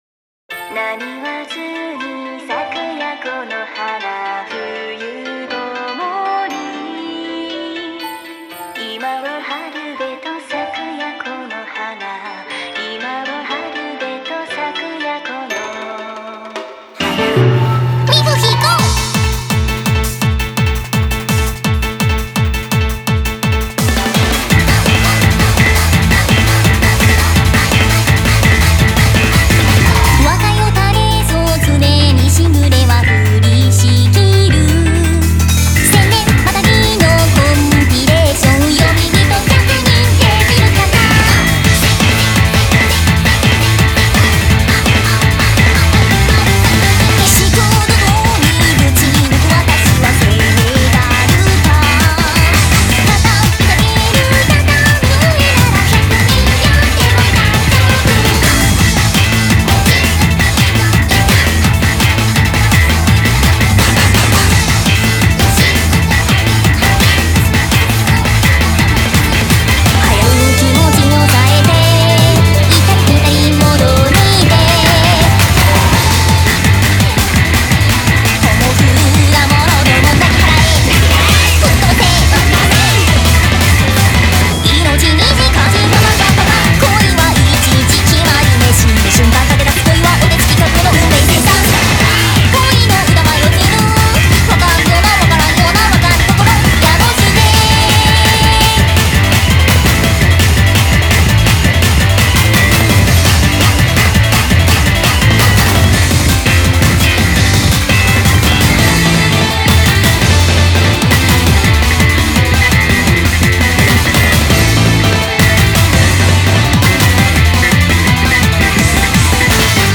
BPM120-168